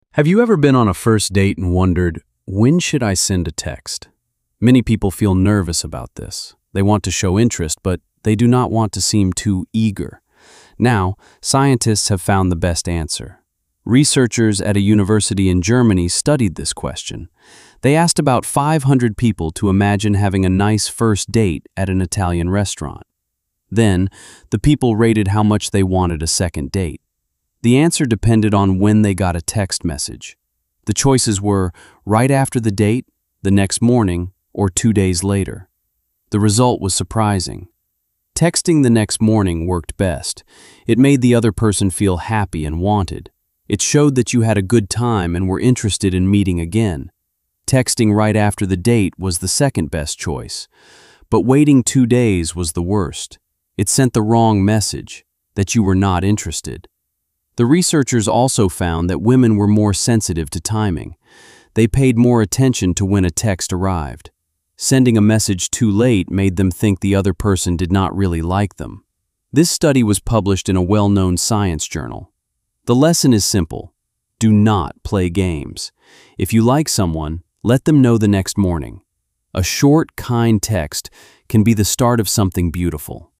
🔊 音読用音声